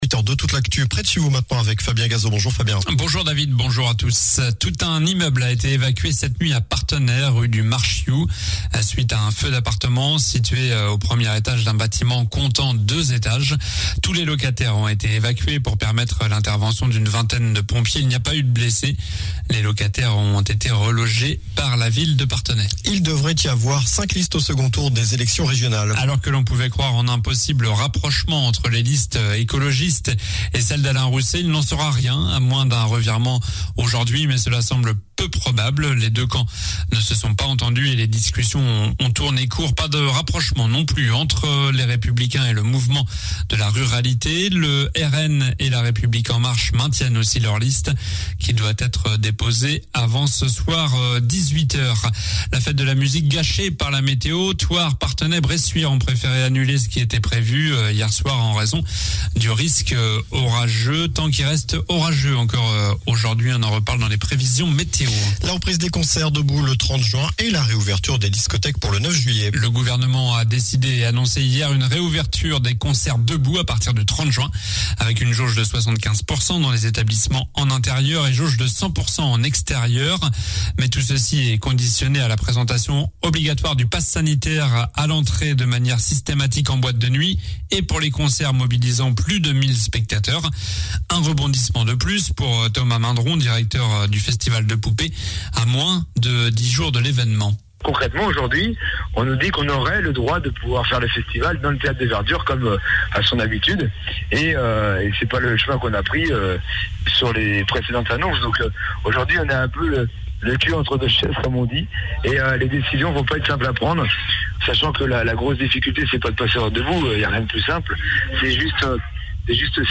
Journal du mardi 22 juin (matin)